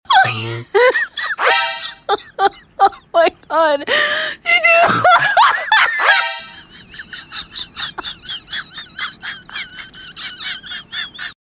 cat 🐈 reaction 😂 😆 sound effects free download